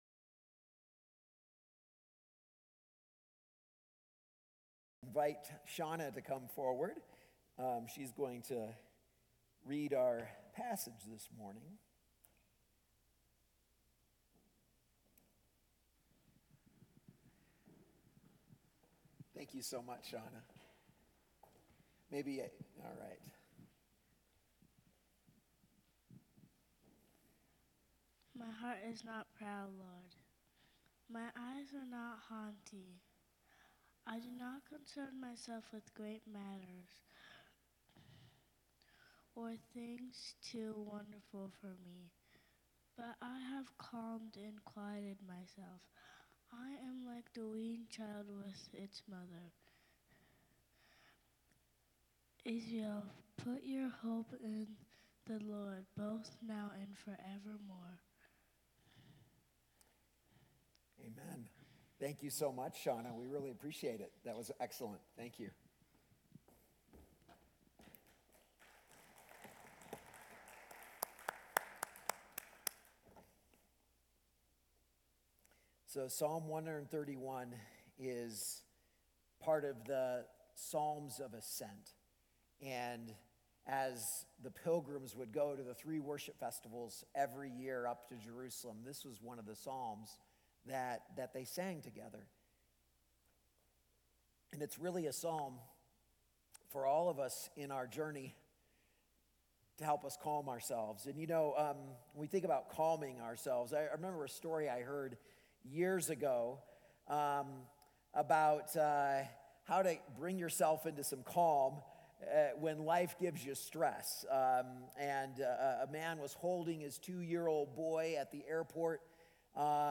A message from the series "Dearly Loved."